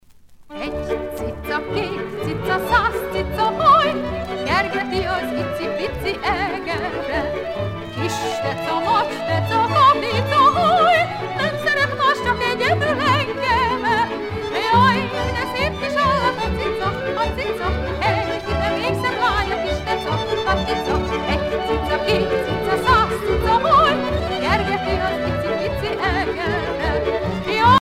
danse : csárdás (Hongrie)